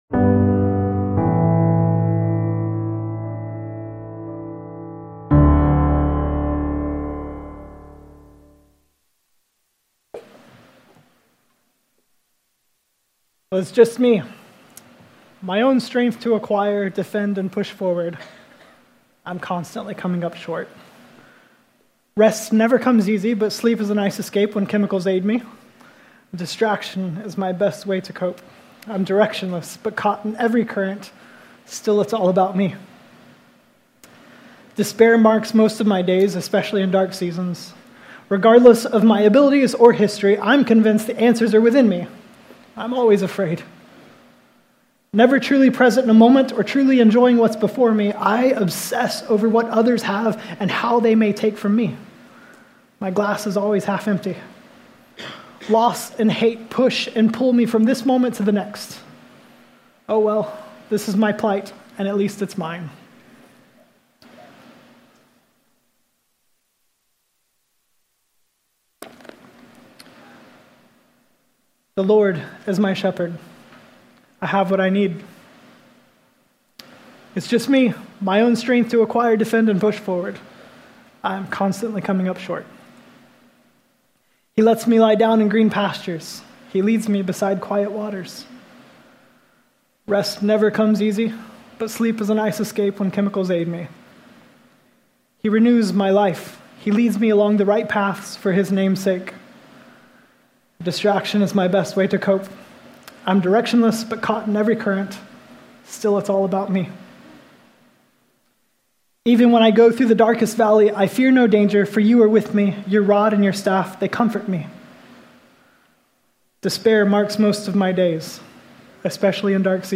5-19-24-Sermon-Audio.mp3